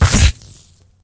~tele_damage_0.ogg